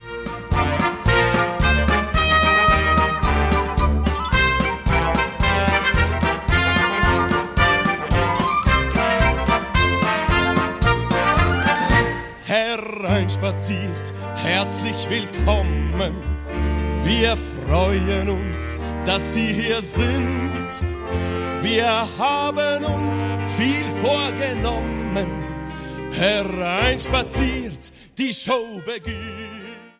Mundartlieder für Chinderchile